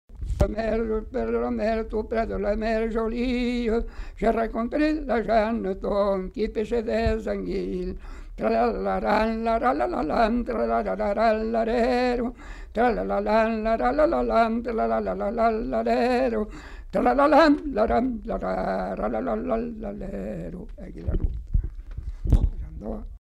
Aire culturelle : Savès
Genre : chant
Effectif : 1
Type de voix : voix d'homme
Production du son : chanté ; fredonné